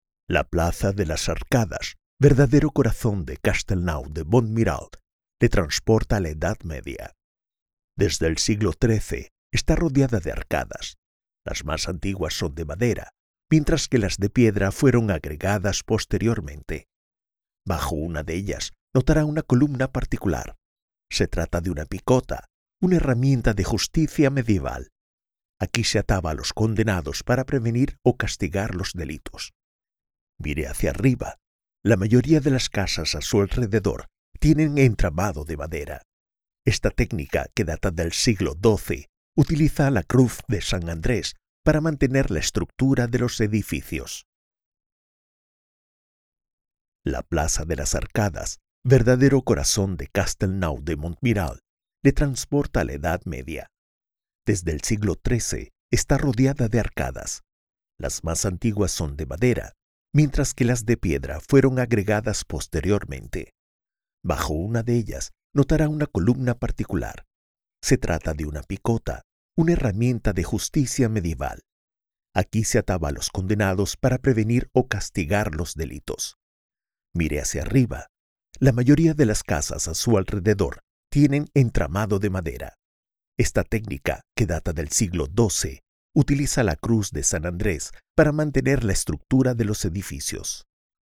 Comercial, Joven, Cool, Versátil, Empresarial
Audioguía